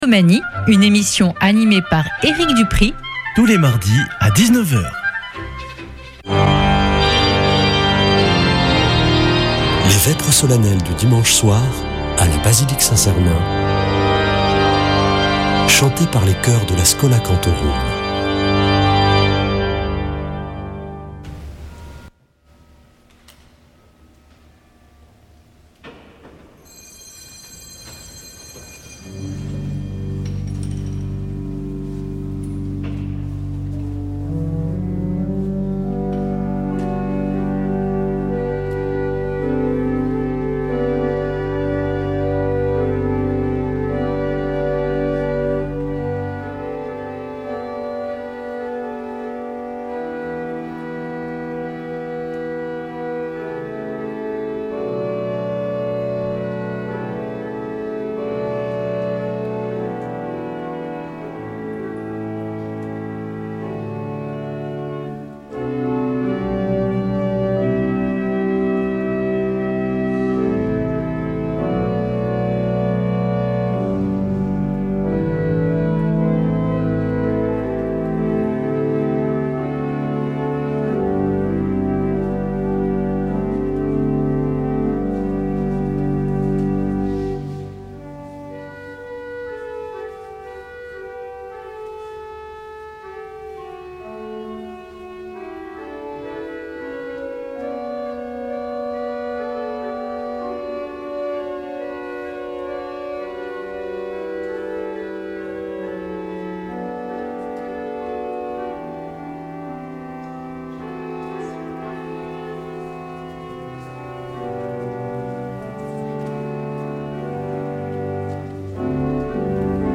Vêpres de Saint Sernin du 07 déc.
Une émission présentée par Schola Saint Sernin Chanteurs